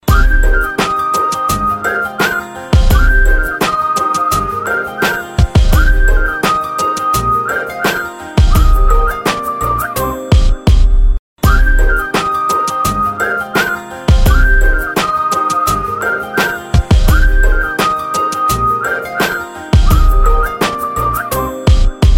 Mix , Whistle , Android , Remix